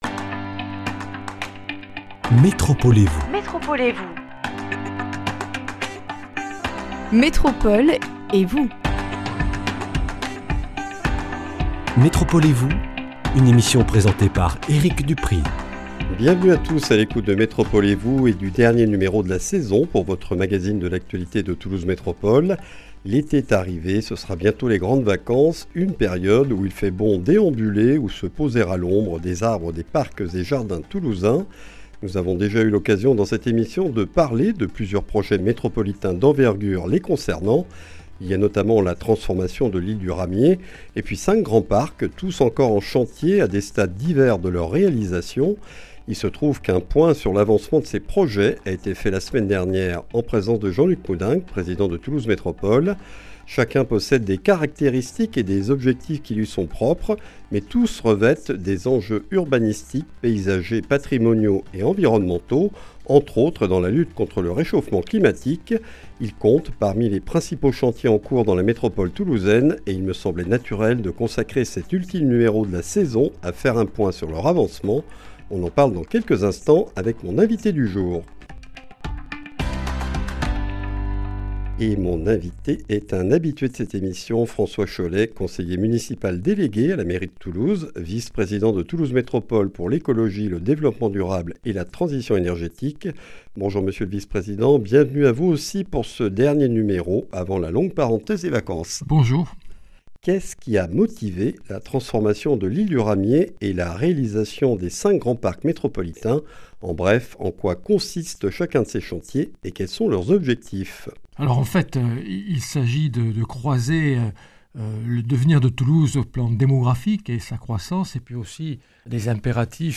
La transformation de l’Île du Ramier et la réalisation des 5 grands parcs métropolitains (Garonne,.Canal, Hers, Touch et La Margelle) comptent parmi les principaux projets en cours dans la métropole toulousaine. Ils présentent à des degrés divers des dimensions paysagères, urbanistiques, patrimoniales et écologiques, en intégrant une réflexion sur les mobilités. Nous faisons le point sur leur avancement avec François Chollet, conseiller municipal délégué à la mairie de Toulouse, vice-président de Toulouse Métropole chargé du Développement durable, de l’Écologie et de la Transition énergétique.